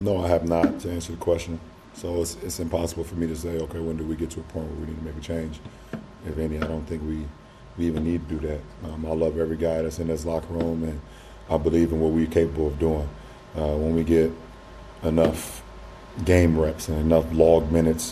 LeBron James was asked about Vogel after practice on Monday and did not hold back.
While many are quick to blame head coach Frank Vogel for the team’s struggles, Lakers star LeBron James defended him while speaking to reporters Monday.